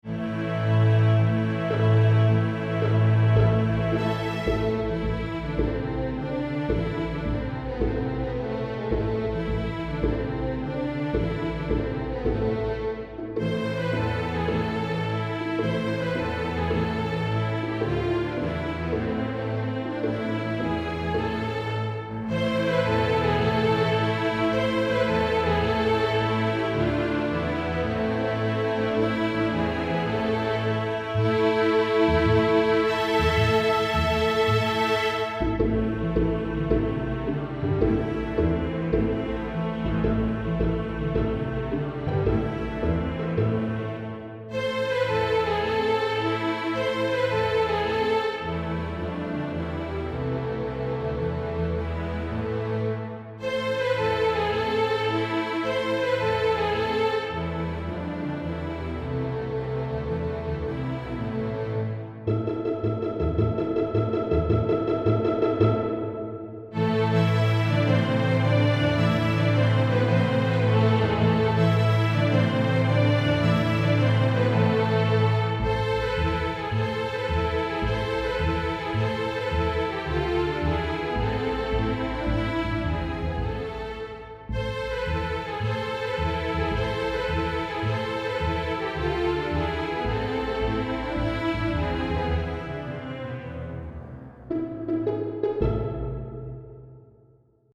INTERMEDIATE, STRING ORCHESTRA
Notes: double stops, pizz, col legno
Key: A minor